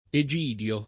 vai all'elenco alfabetico delle voci ingrandisci il carattere 100% rimpicciolisci il carattere stampa invia tramite posta elettronica codividi su Facebook Egidio [ e J& d L o ; sp. e K&DL o ] pers. m. — sim., in it., i cogn.